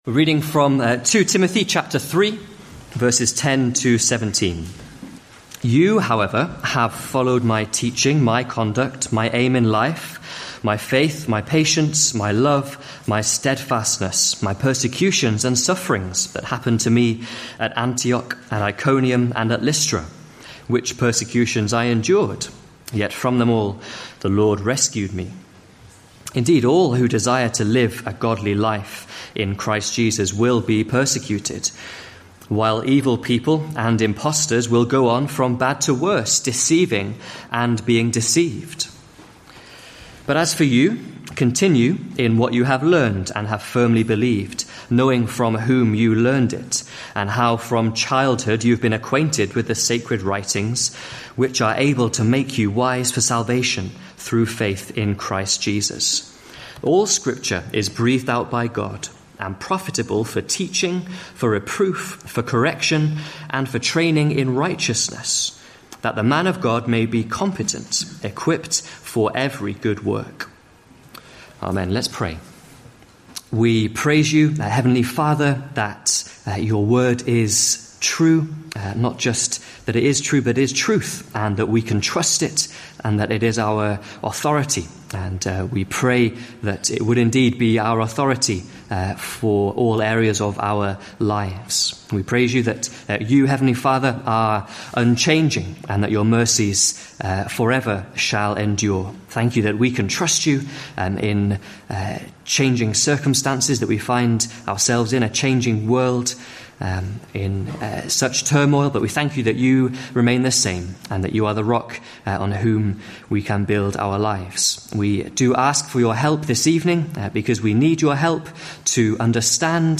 2018 Autumn Lectures